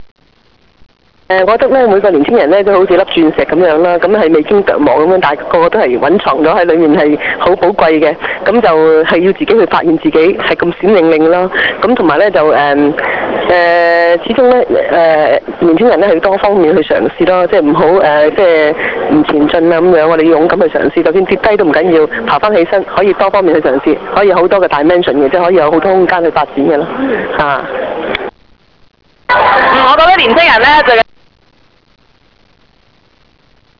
由青少年暑期活動員會及傑出青年協會合辦的「飛躍人生」講座經已於十一月二十日完滿結束。
當晚香港大球場體育大樓的賽馬會演講廳座無虛設，參加者多數是青少年。